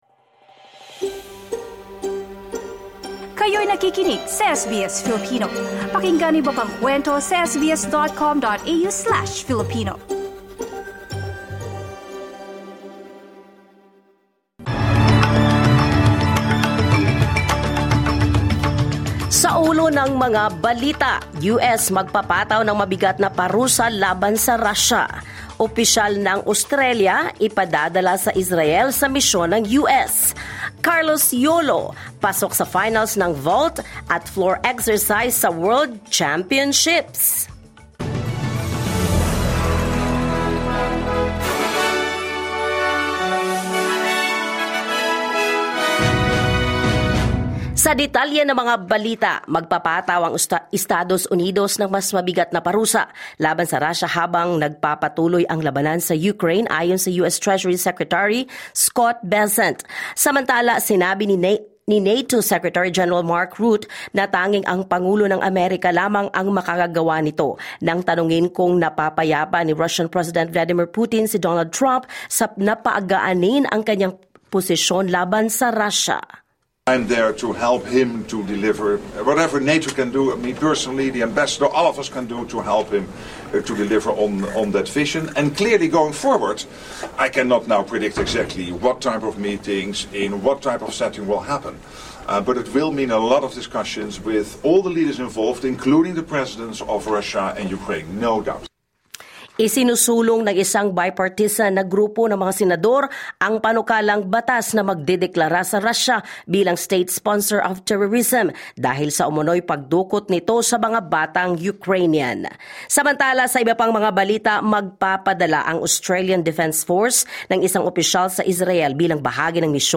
SBS News in Filipino, Thursday 23 October 2025